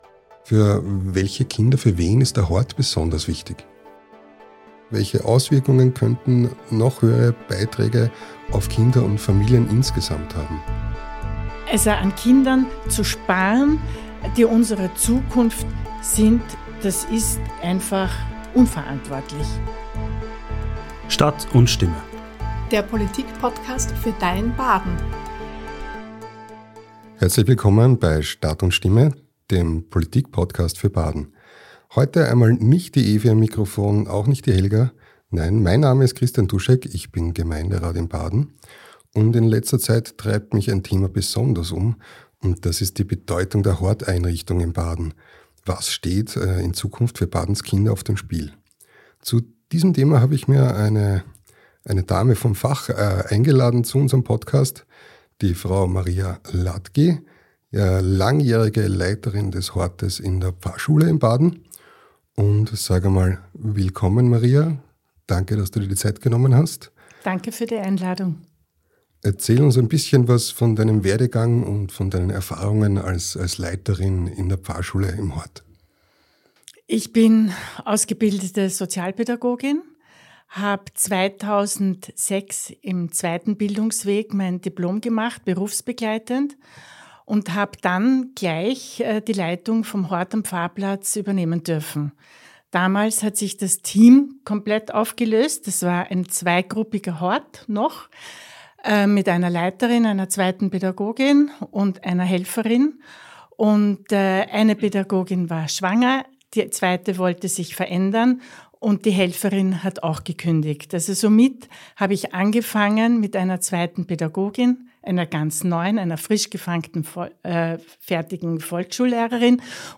Gastgeber Gemeinderat Christian Dusek